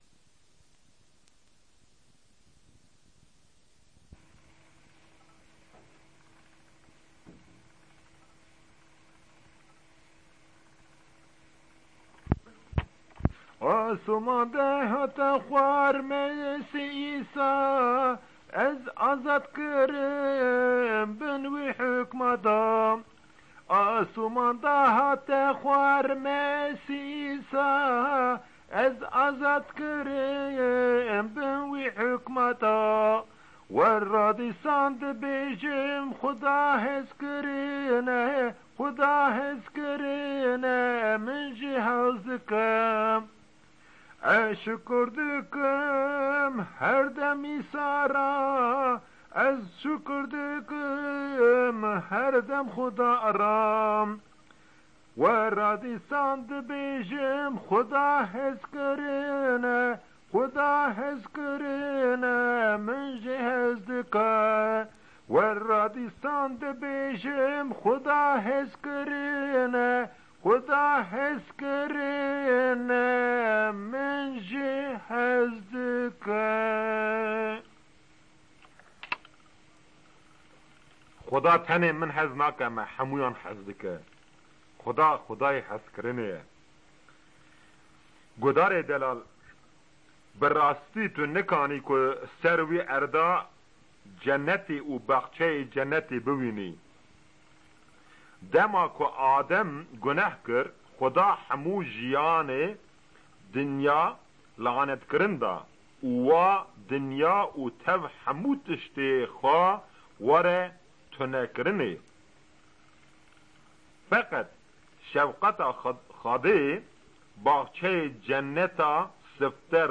Sermon_Kurdi_Kurmanji_C33891B.mp3